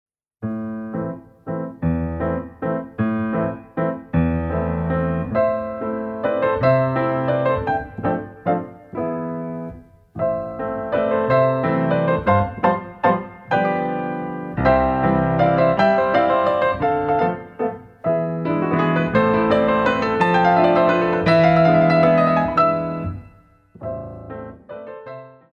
Exercice de pirouette